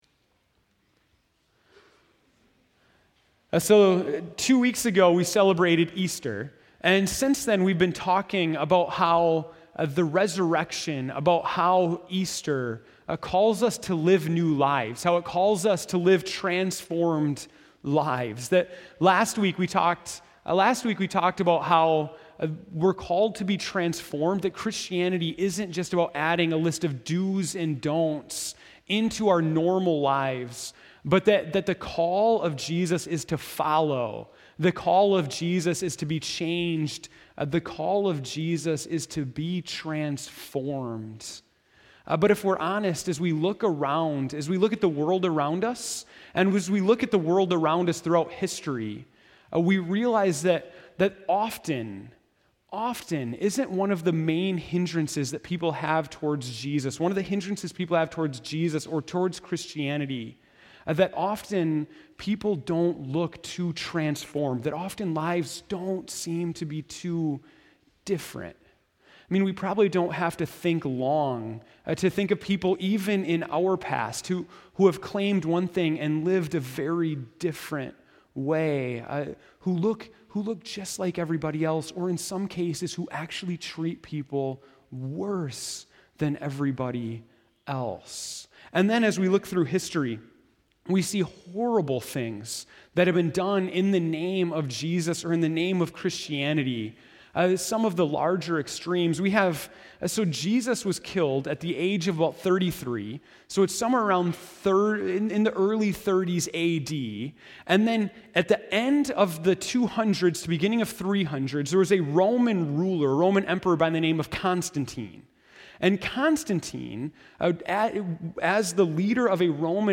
April 19, 2015 (Morning Worship)